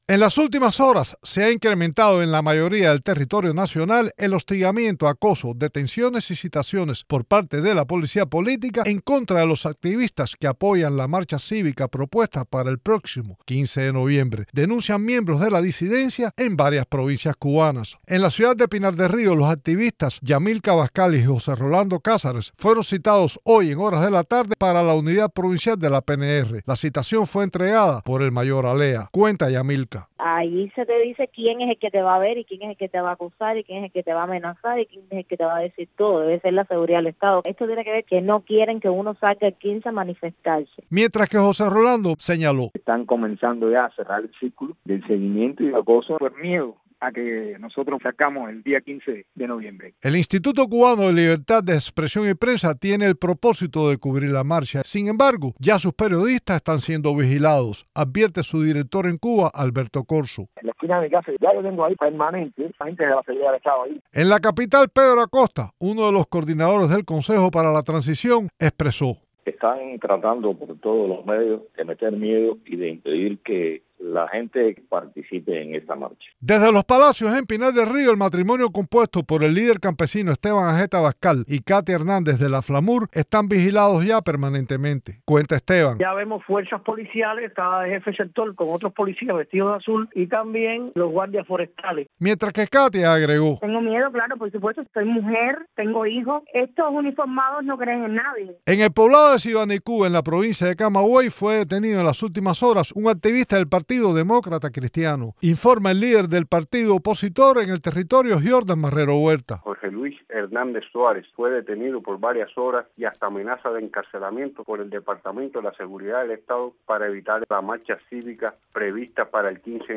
Reportaje radial